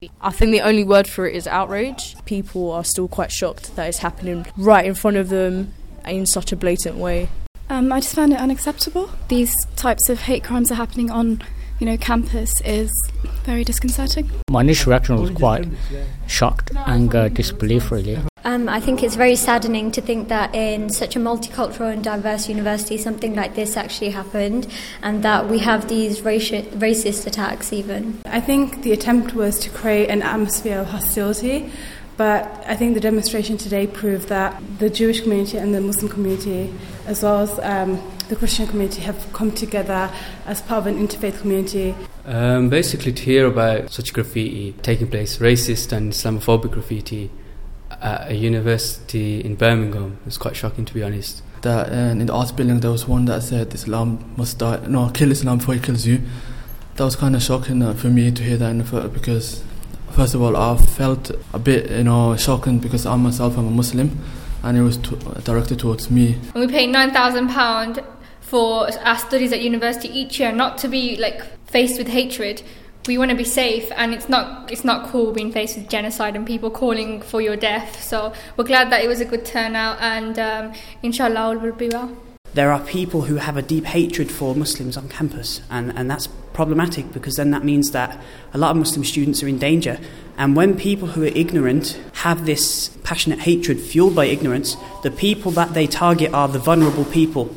University of Birmingham students react to Islamophobic graffiti attacks
They voiced their concerns through this demonstration after racist messages such as “Islam Must Die” and swastikas were seen on the side of the Psychology building and inside university toilets.